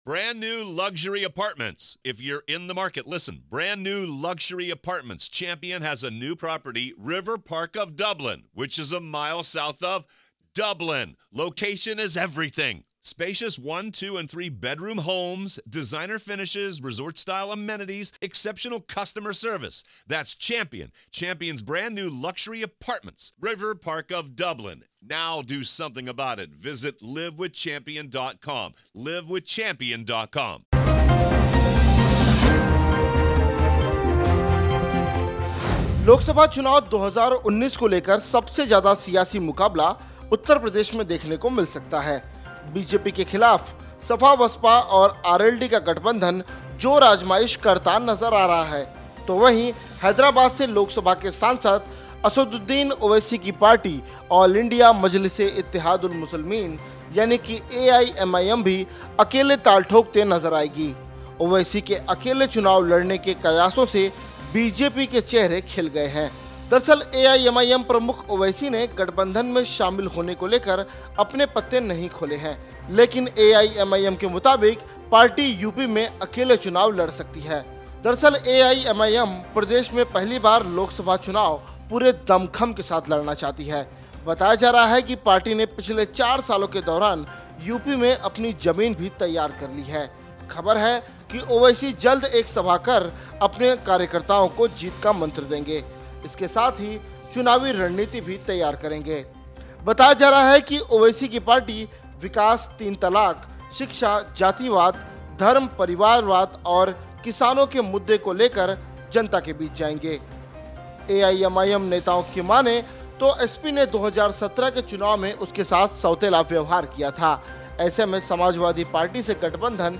न्यूज़ रिपोर्ट - News Report Hindi / यूपी में ओवैसी की पार्टी अकेले लड़ेगी लोकसभा चुनाव !